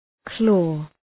Shkrimi fonetik {klɔ:}